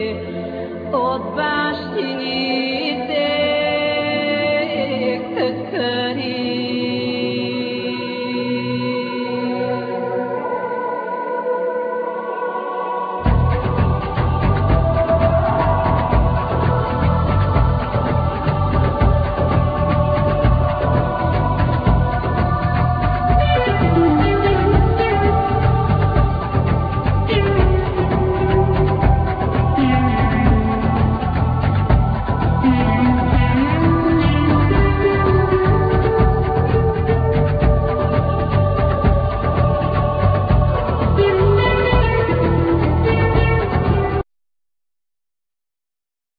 Guitar,Guitar synth
Bass
Percussion
Soprano saxphone,Flute
Lyre